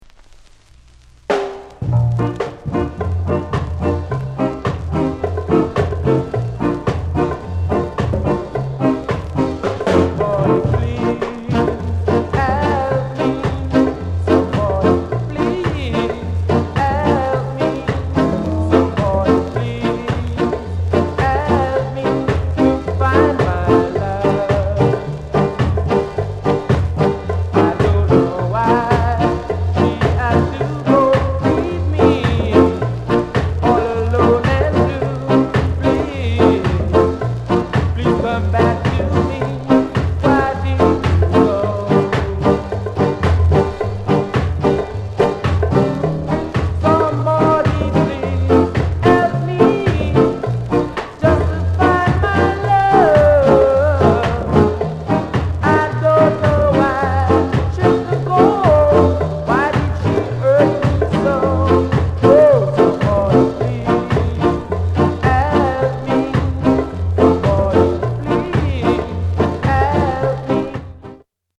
RARE SKA